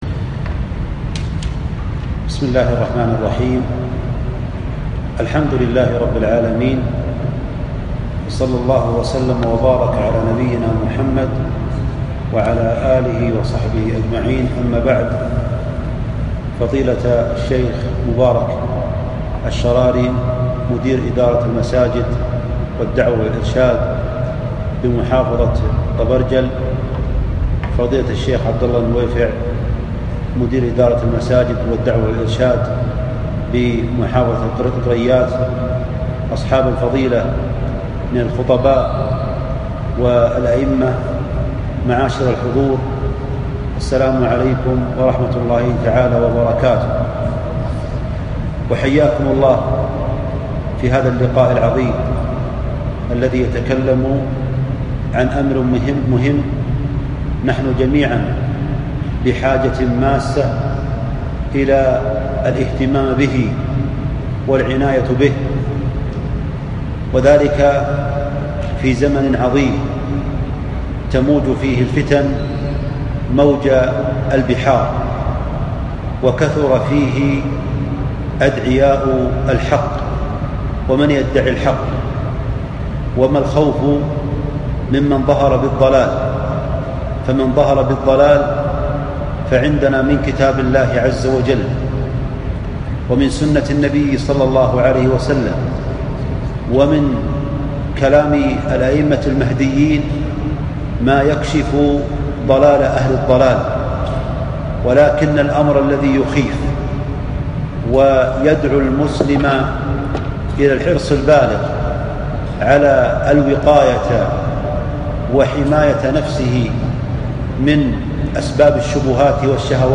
محاضرة دورة الأئمة والخطباء في توعية المجتمع ضد أفكار جماعات العنف والغلو